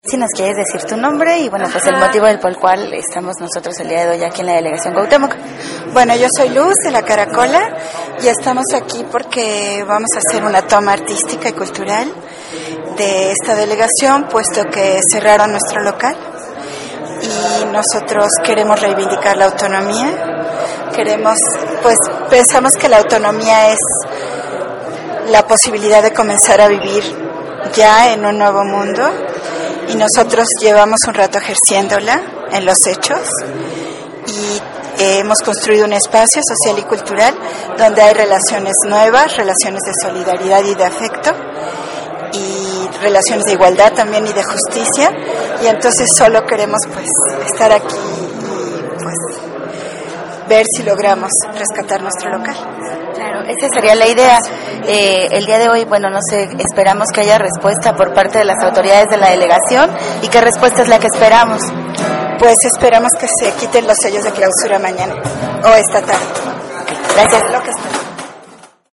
Ante esta  agresión, integrantes de La Karakola y compañerxs solidarixs, llevaron a cabo un acto-protesta ante la Jefatura de la Delegación Cuauhtémoc con el fin de exigir el retiro de sellos de clausura del espacio autónomo liberado.